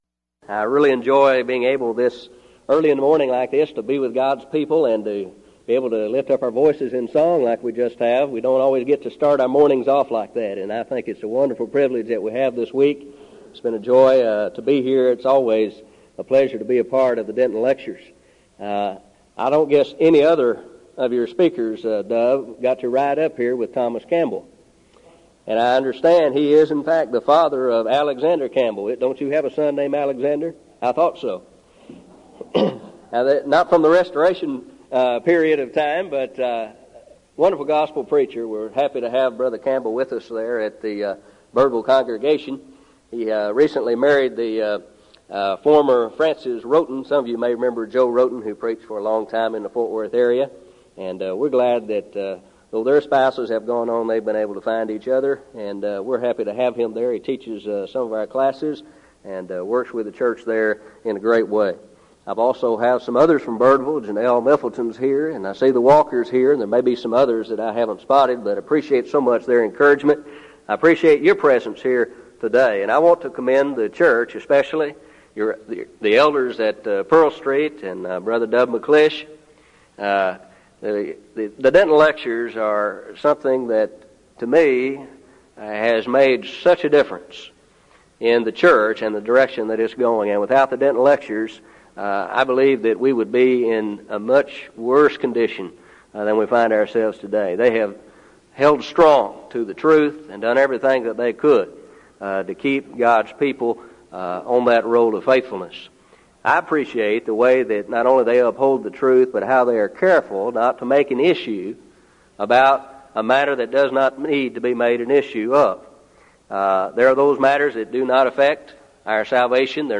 Event: 1998 Denton Lectures
lecture